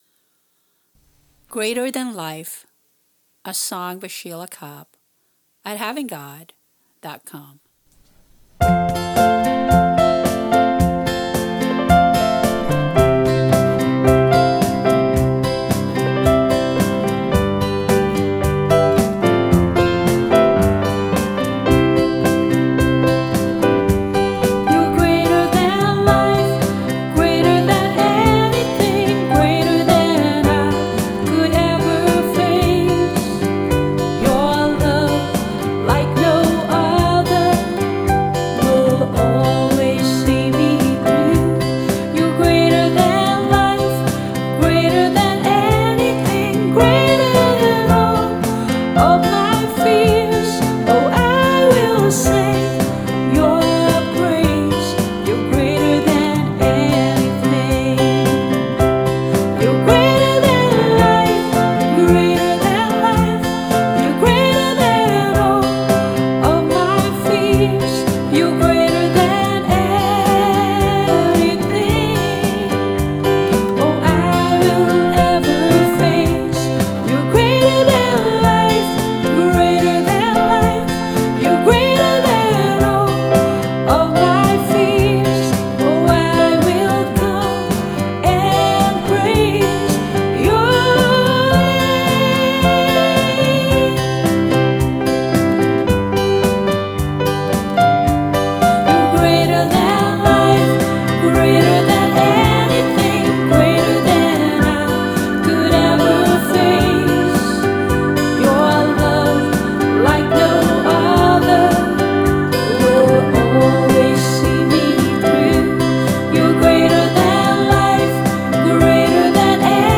Vocals, guitar, bass and drum machine
Keyboard and organ